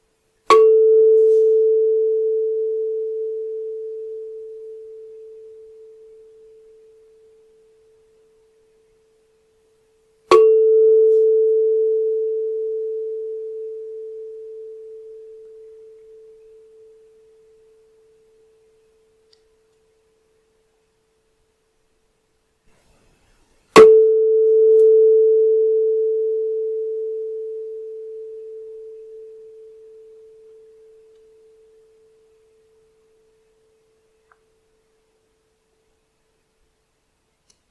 Камертон нота ми для настройки гитары